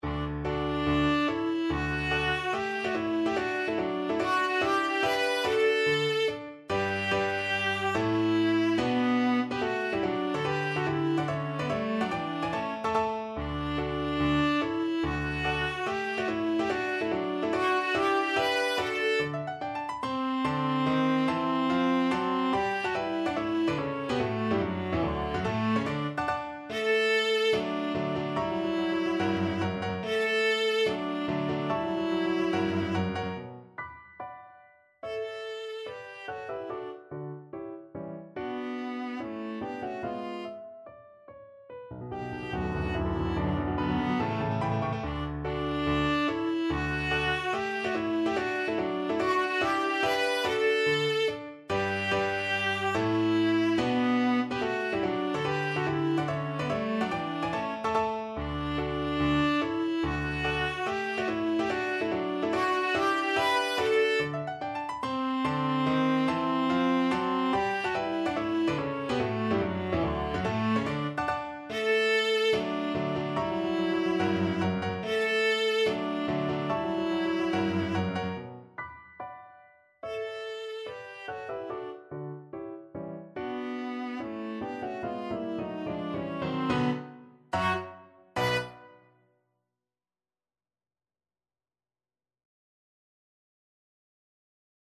4/4 (View more 4/4 Music)
Allegro non troppo (=72) (View more music marked Allegro)
Classical (View more Classical Viola Music)